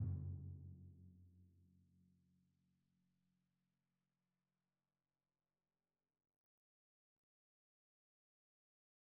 Percussion / Timpani
Timpani1_Hit_v3_rr1_Sum.wav